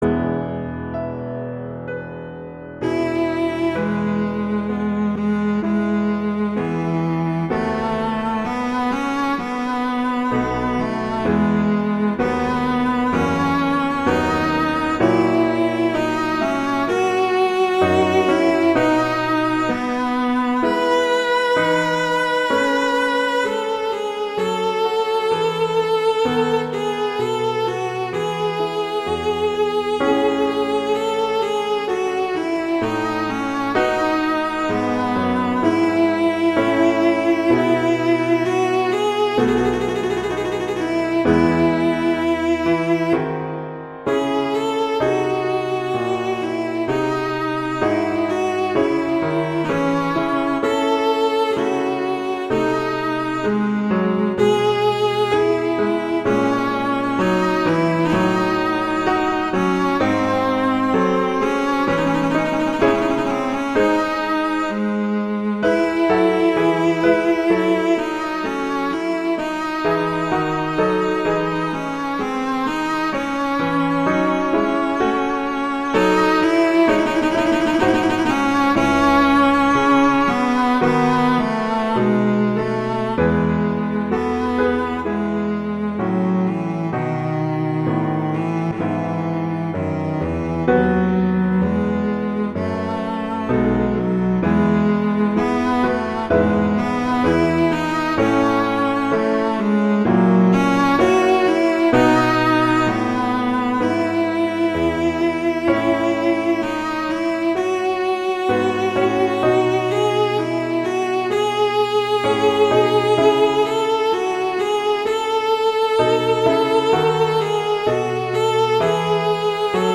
cello and piano
classical
Adagio